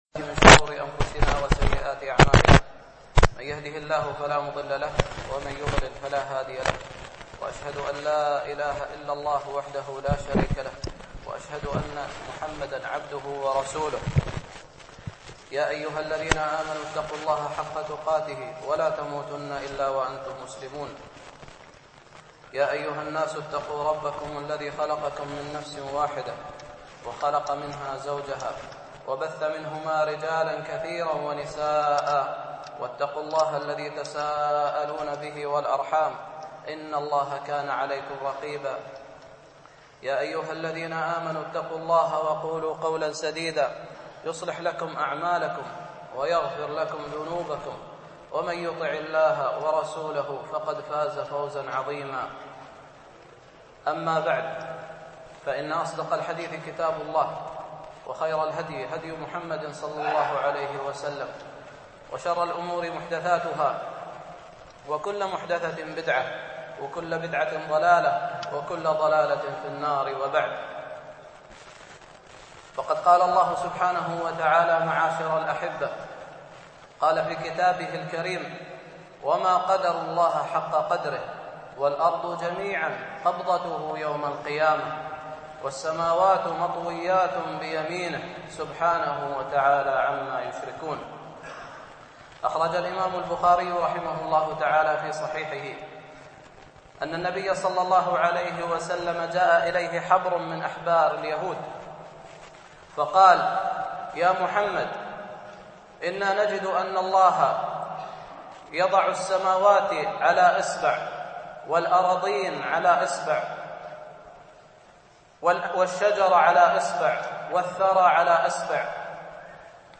الألبوم: دروس مسجد عائشة (برعاية مركز رياض الصالحين ـ بدبي)
MP3 Mono